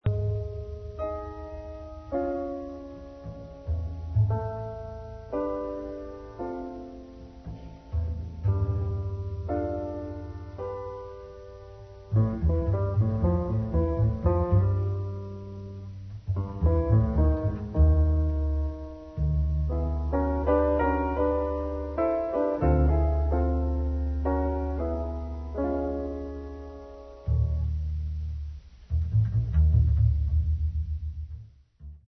Les trompettistes